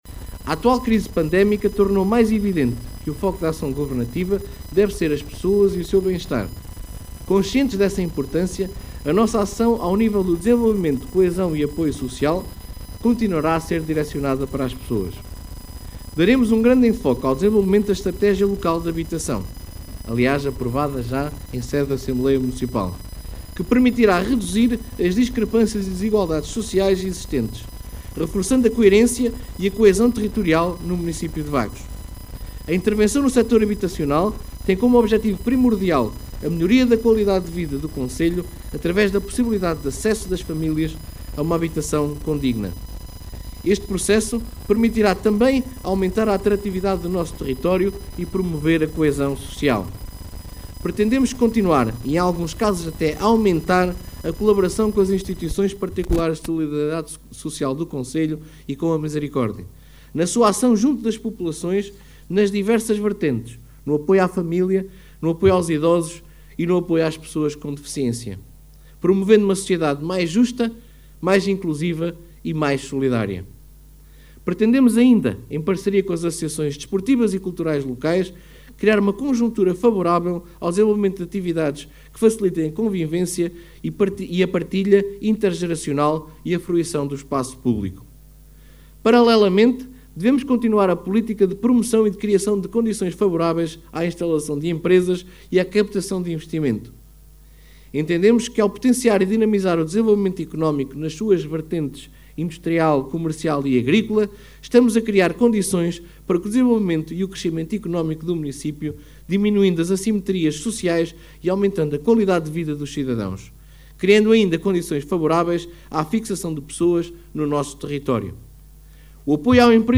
A cerimónia decorreu ao final da tarde, no Centro de Educação e Recreio (CER) de Vagos.